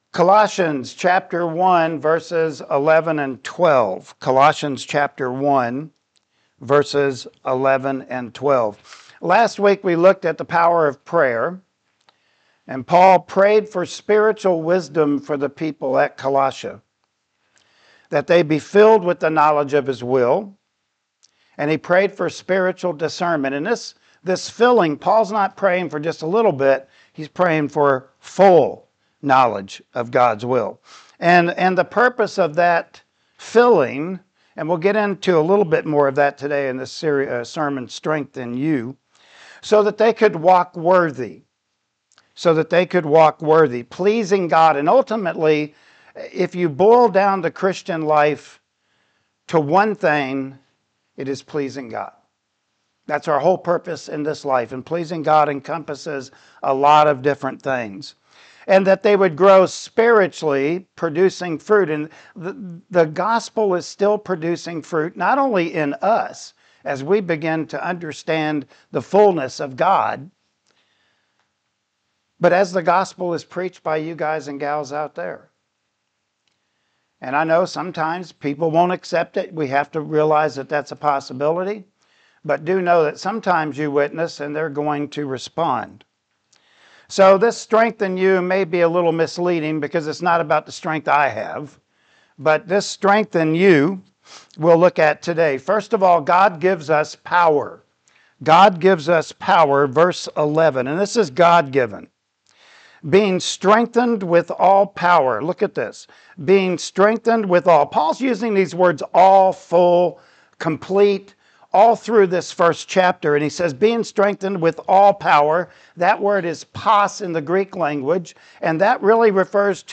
Colossians Passage: Colossians 1:11-12 Service Type: Sunday Morning Worship Service Topics